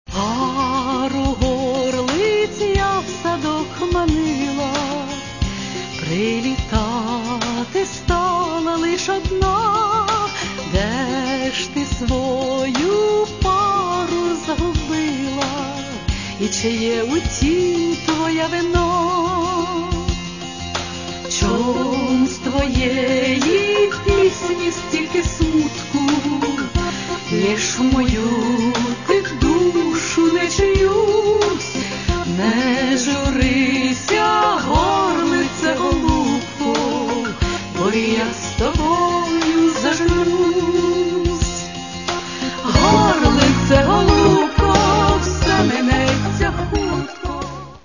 Каталог -> Естрада -> Дуети